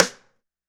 ST DRYRIM3.wav